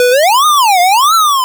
retro_synth_wobble_03.wav